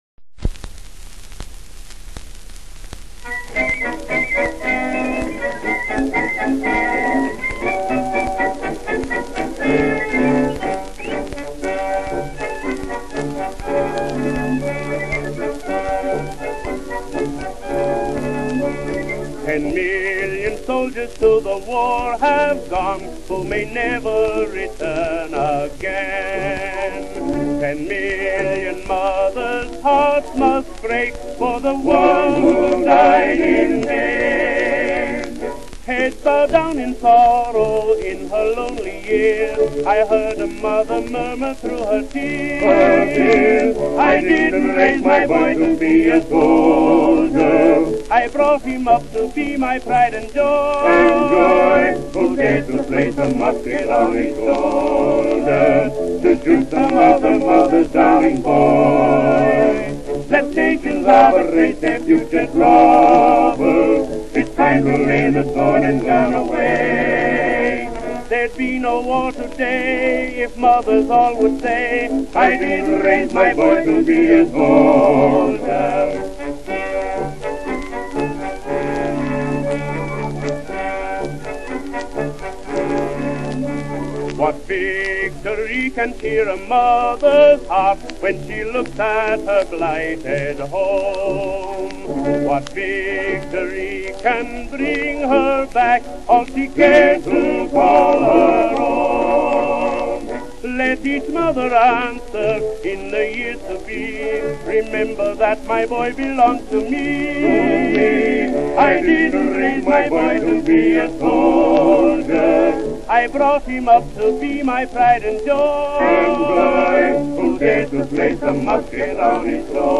There were antiwar songs, like